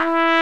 TRUMPET 2 E3.wav